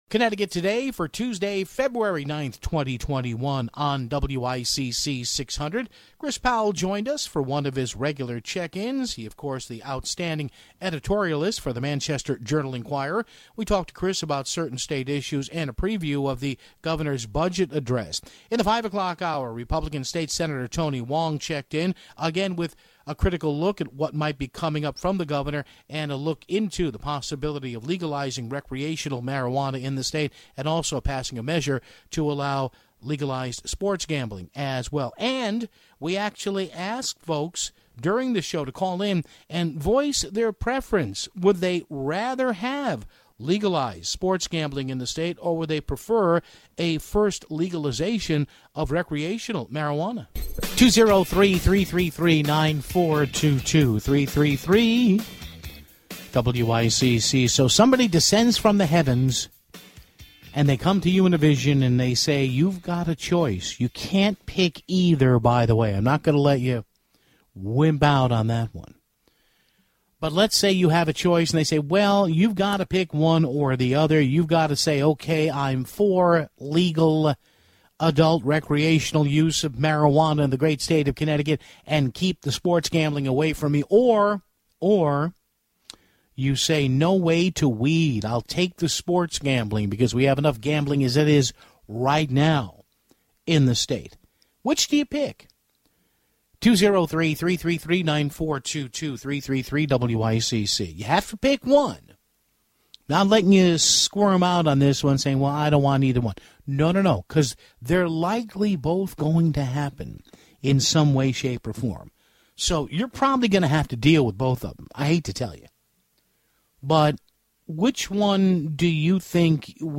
Finally, Fairfield GOP State Senator Tony Hwang gave his takes on the budget as well as what happened between him and flavored tobacco critic (39:39)